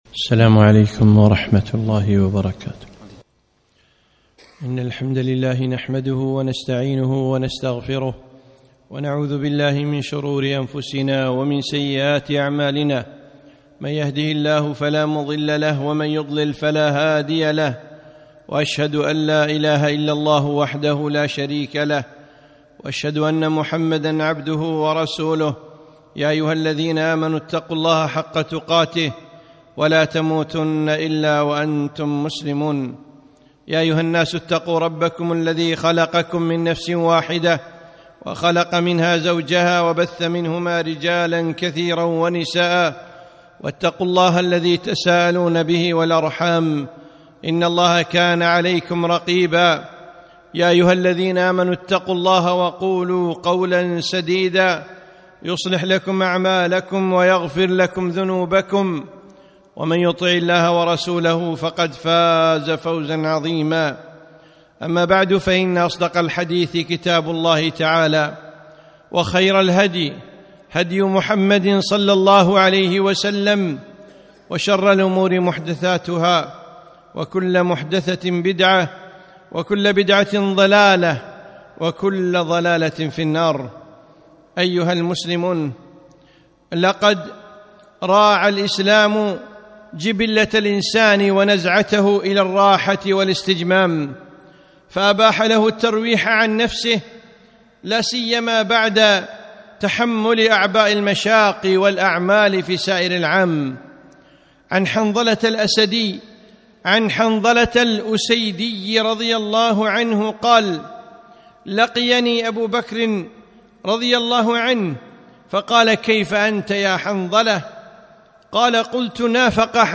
خطبة - اغتنام الأوقات